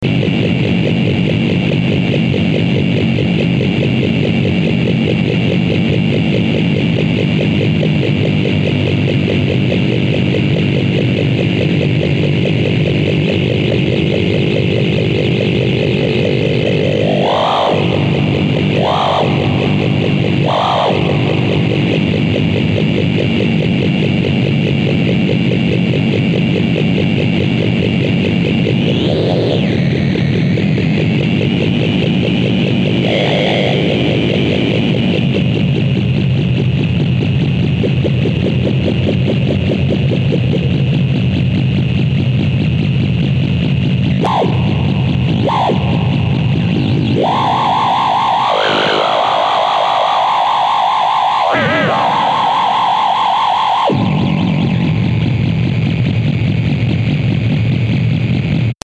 Yesterday, I made a test of the Kastle into a guitar amp and into the DAW via USB - no preamp so to speak